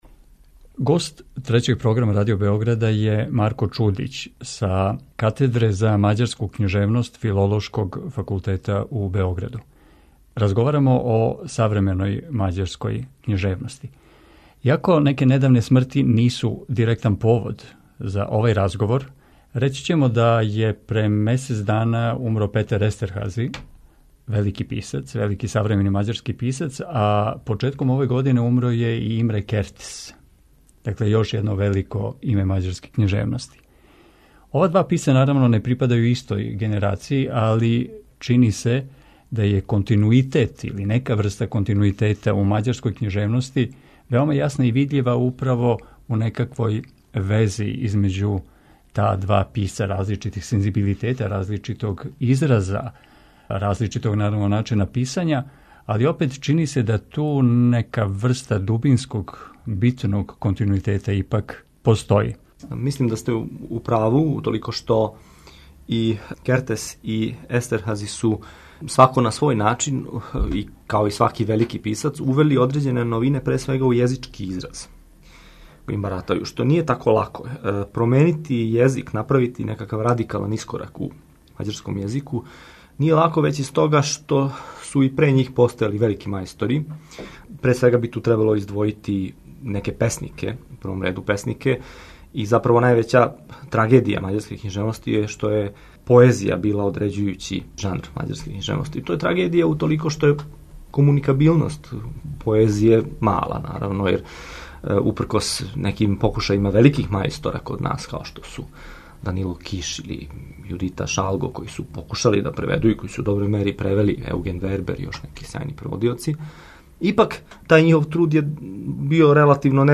преузми : 17.47 MB Радијска предавања, Дијалози Autor: Трећи програм Из Студија 6 директно преносимо јавна радијска предавања.